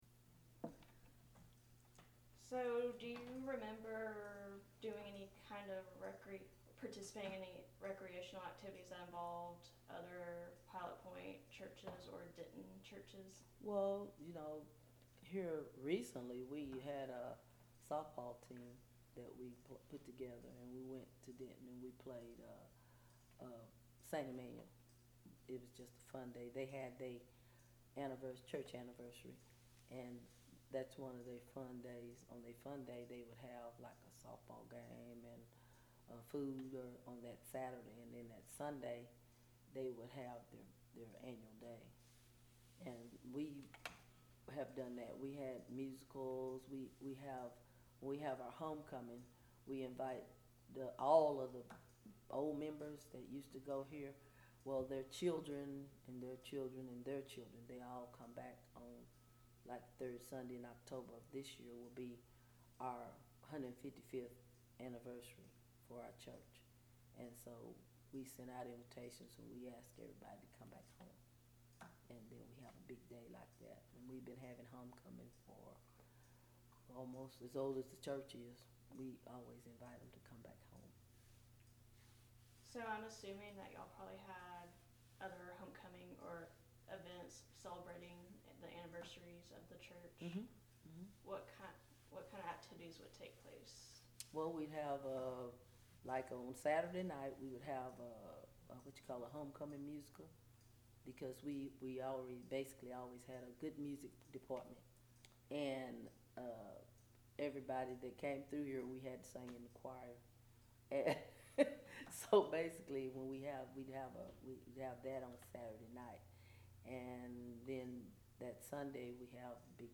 Oral History Clip
Interview